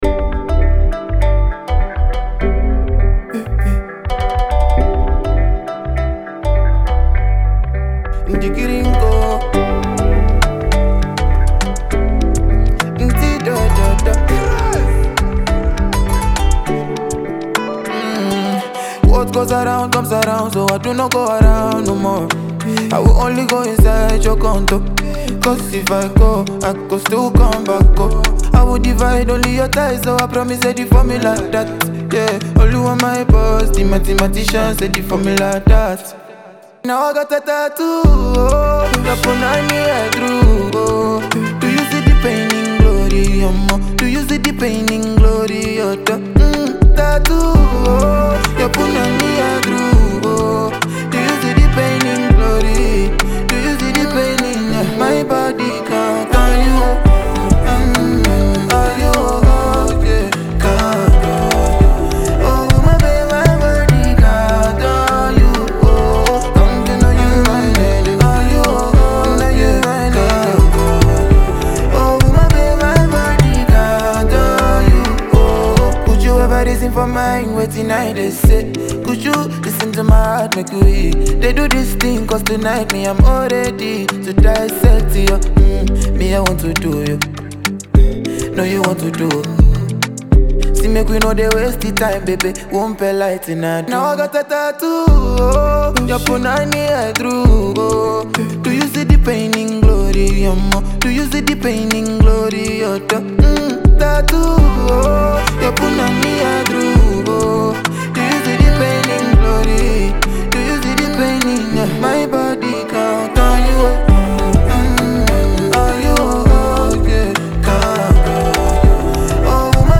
” which is smooth, vibey, and impossible to ignore.
with a clean, mellow touch
Simple, catchy, and deeply emotional.